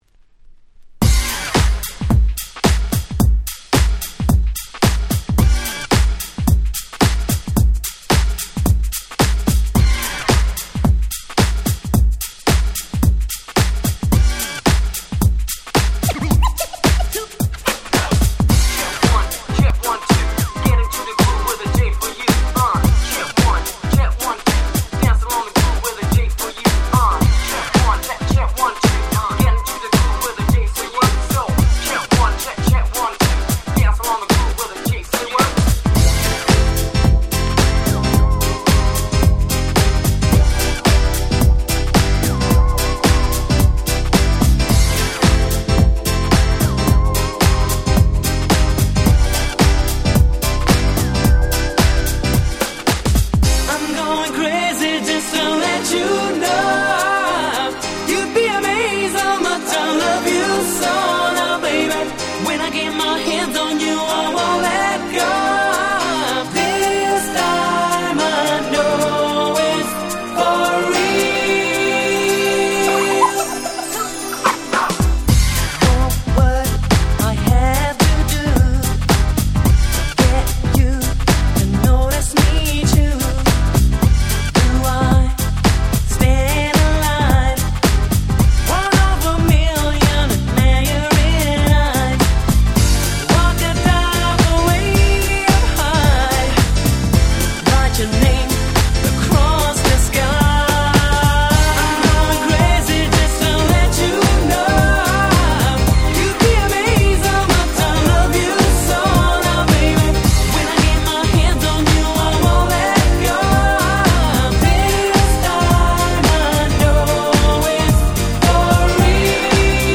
97' Super Nice Cover R&B !!
コチラのジャケ付き盤は青ジャケ盤に比べイントロが長くロングミックスに最適！！
キャッチー系